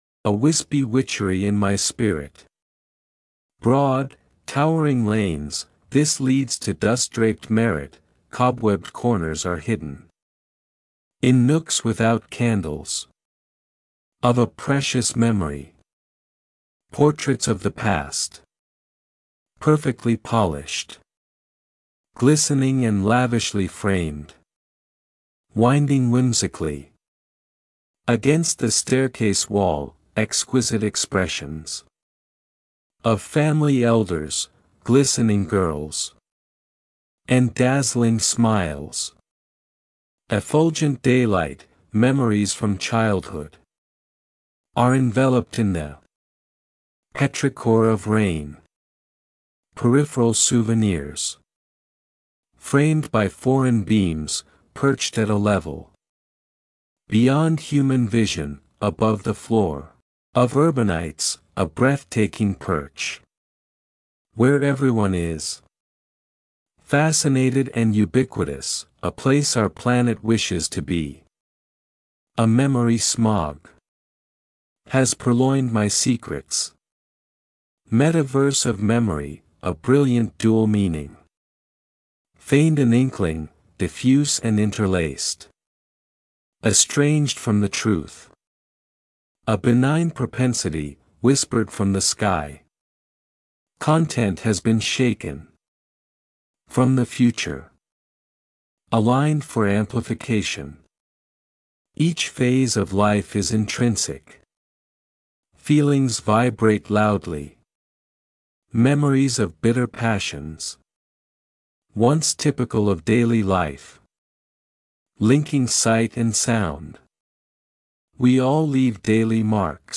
I love the orchestral music too.